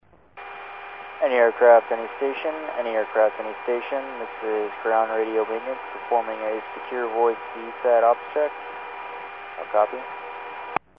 HF Radio and Satcom Monitoring
260.725 CONUS: Ground Radio Maintenance conducting satcom ops check.